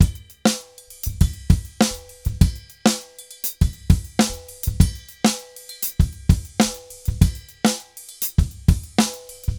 Drums_Candombe 100_4.wav